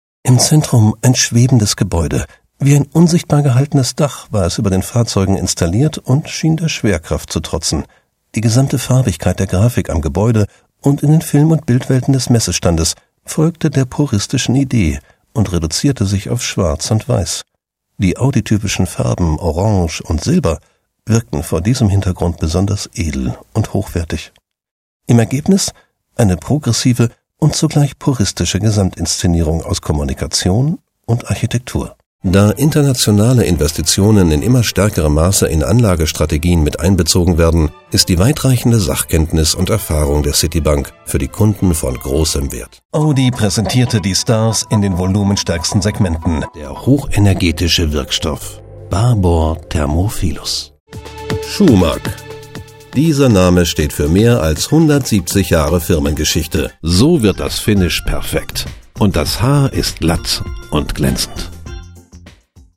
deutscher Sprecher für Nachrichten, TV-Features, Magazinbeiträge, Imagefilme, Produktfilme, Schulungsfilme, Werbespots, On-Air-Promotion, Hörspiele, Hörbücher, Synchronrollen.
Kein Dialekt
Sprechprobe: Industrie (Muttersprache):